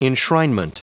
Prononciation du mot enshrinement en anglais (fichier audio)
Prononciation du mot : enshrinement
enshrinement.wav